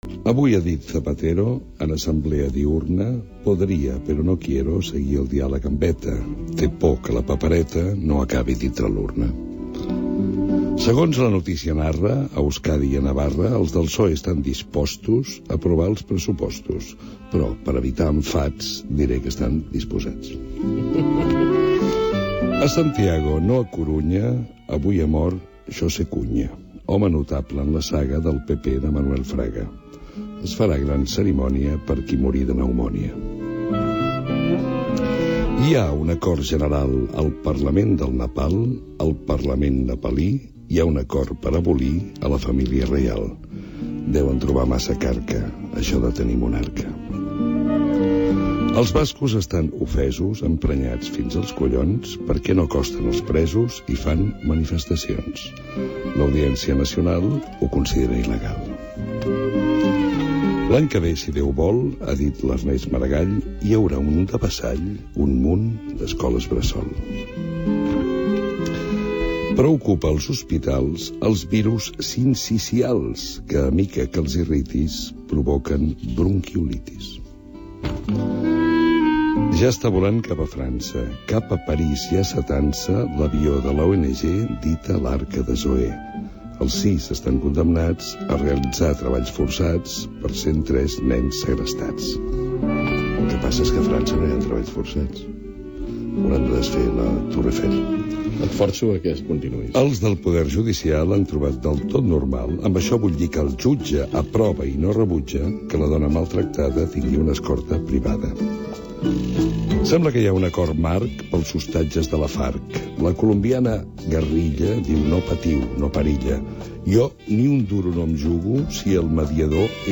Versos d'actualitat Gènere radiofònic Informatiu